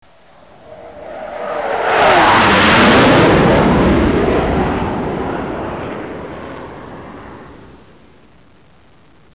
jet.wav